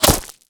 bullet_impact_gravel_08.wav